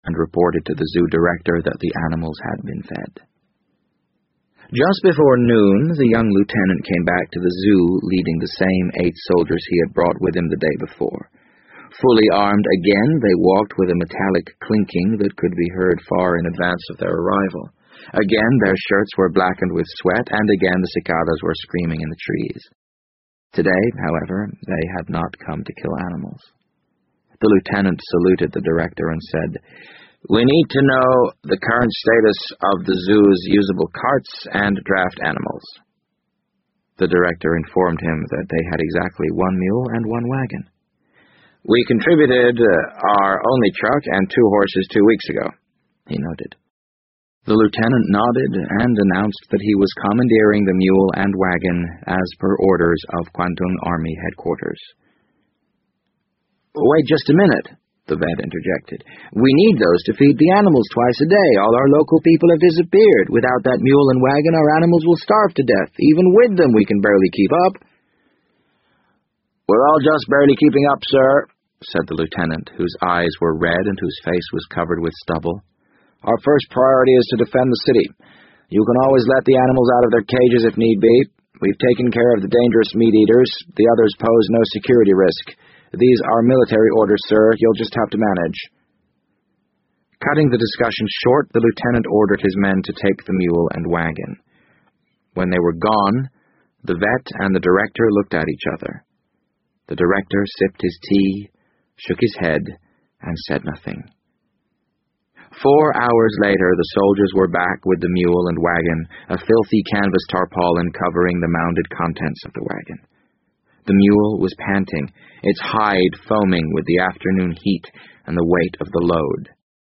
BBC英文广播剧在线听 The Wind Up Bird 013 - 7 听力文件下载—在线英语听力室